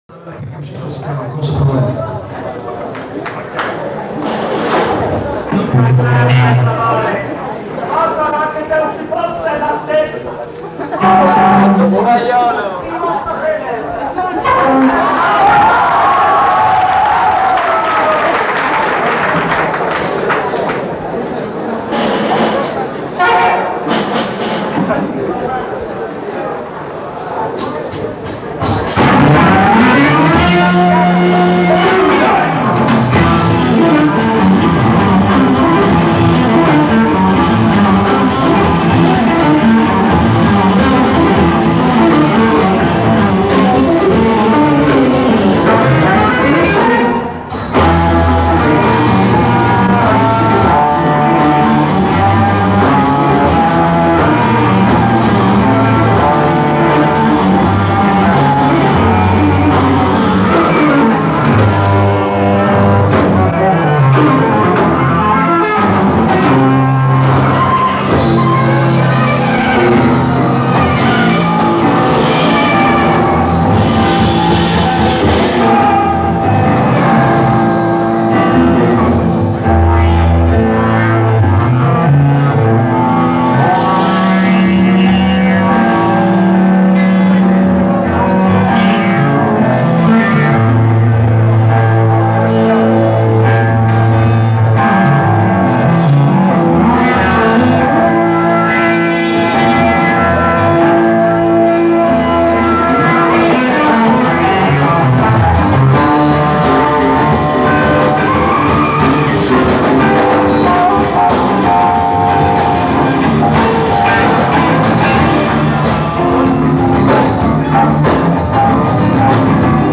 Registrazione privata (mics)
Registrazione: a Firenze, Teatro Astoria, il 23 ottobre 1973